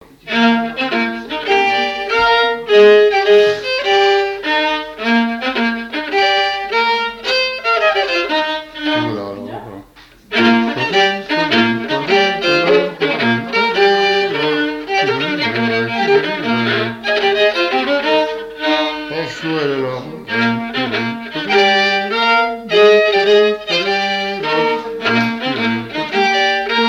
Mareuil-sur-Lay
danse : quadrille : avant-quatre
Le quadrille et danses de salons au violon
Pièce musicale inédite